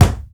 punch_low_deep_impact_06.wav